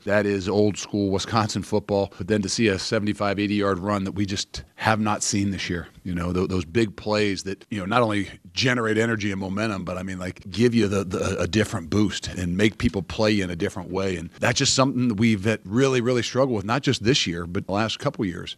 Badgers coach Luke Fickell said the 16-play opening drive set the table in this one.